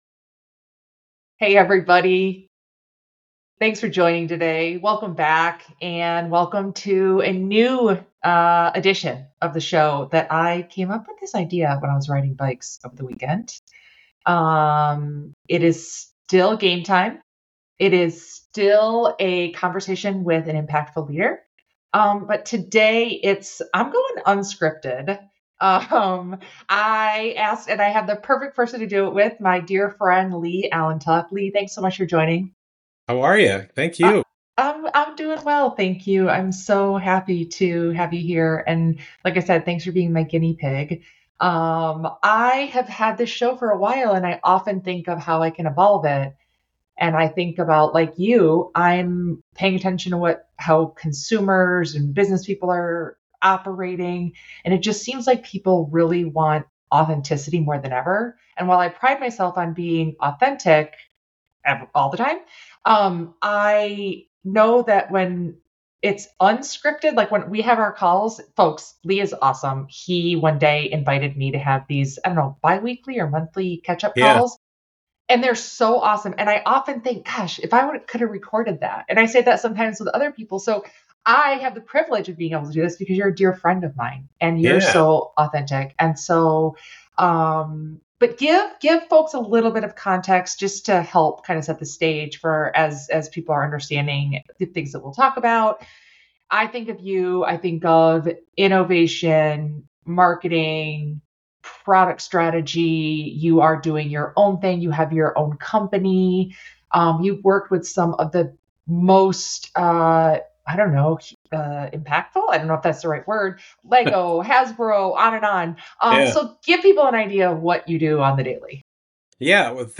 So, tune-in to enjoy these conversations with impactful leaders.